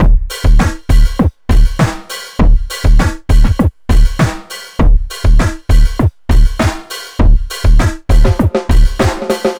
funkis 100bpm 01.wav